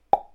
pop01.mp3